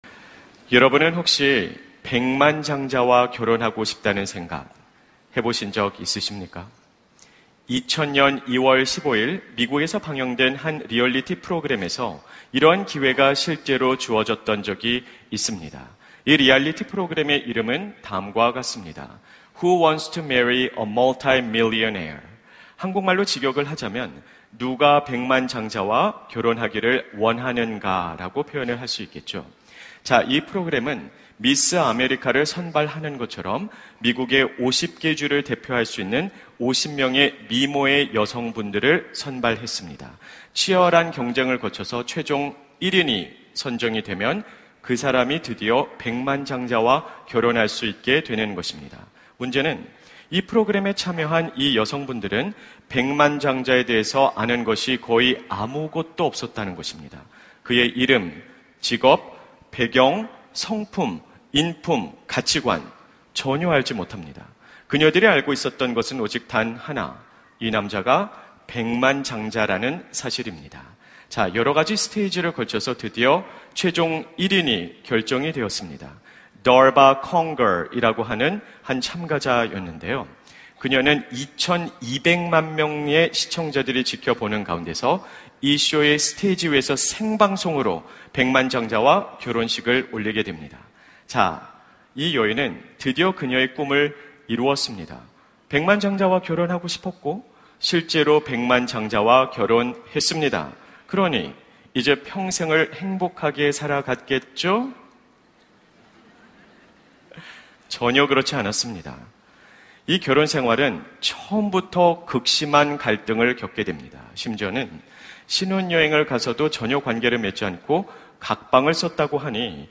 설교 : 주일예배 복음수업 - 복음수업 2 : 복음, 한 마디로! 설교본문 : 누가복음 15:11-24